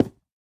Minecraft Version Minecraft Version latest Latest Release | Latest Snapshot latest / assets / minecraft / sounds / block / cherry_wood / break3.ogg Compare With Compare With Latest Release | Latest Snapshot